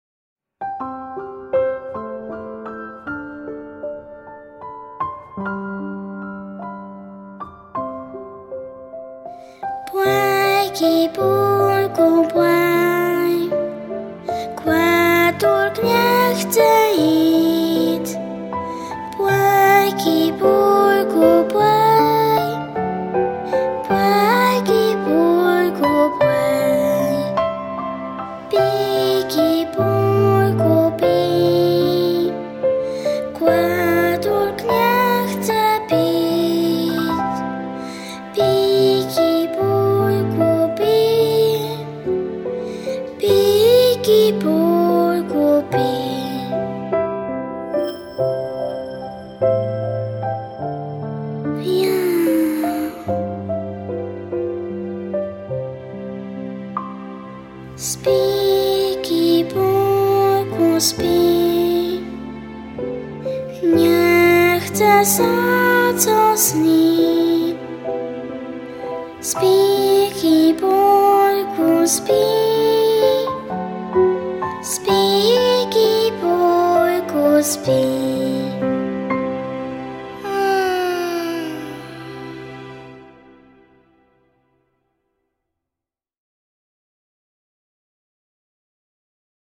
Nagranie wykonania utworu